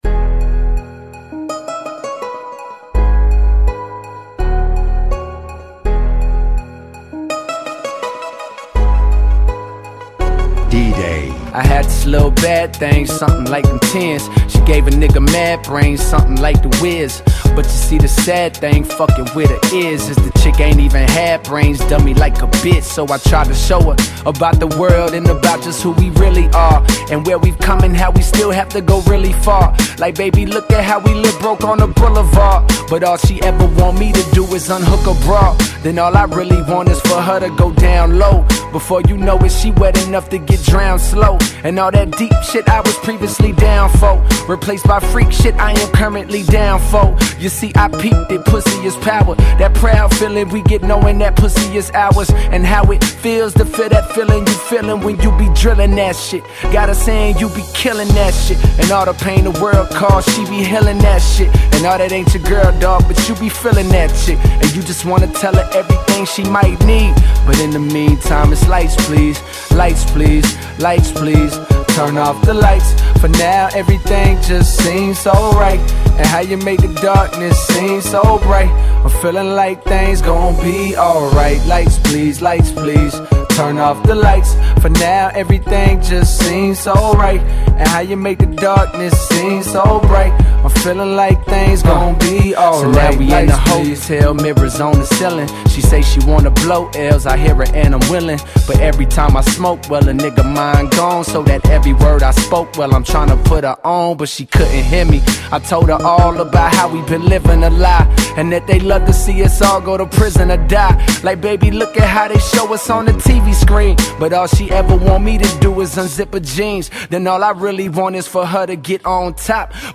Genre: Blends.